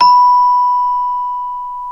CELESTE 2 B4.wav